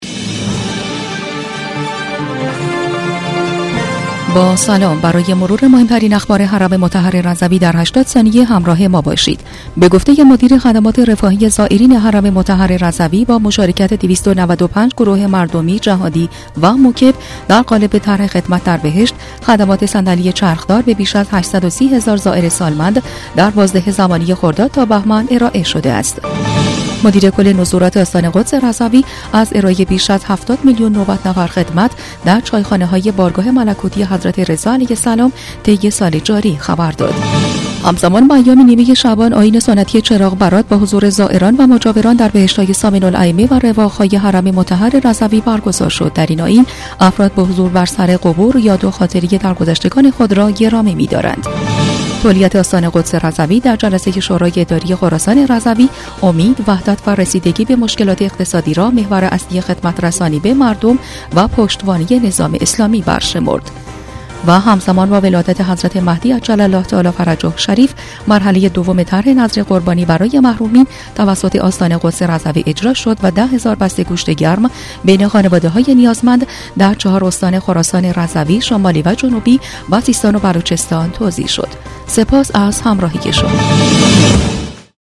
برچسب ها: صوت رادیو رضوی بسته خبری رادیو رضوی